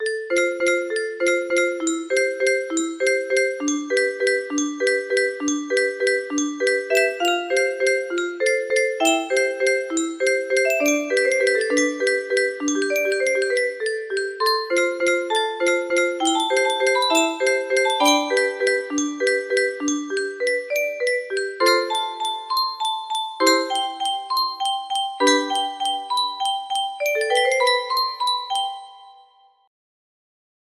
Waltz test music box melody